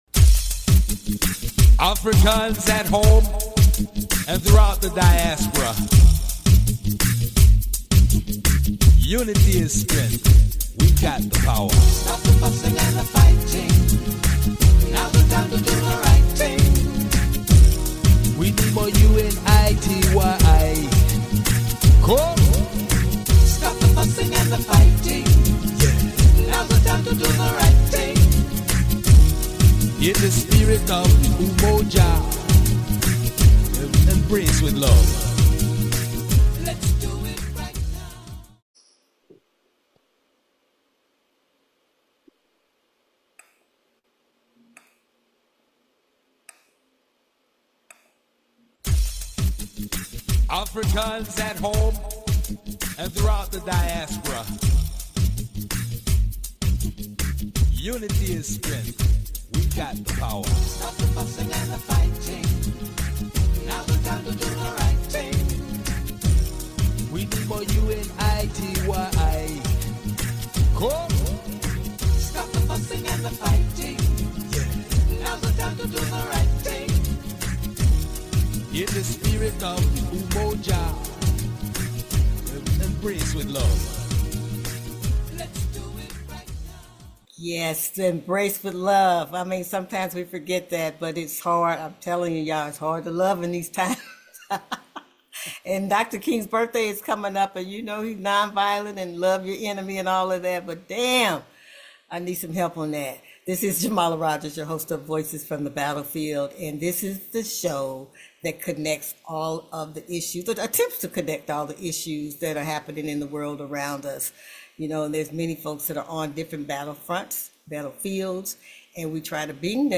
Voices_BC-Roundtable_Jan-7_2026.mp3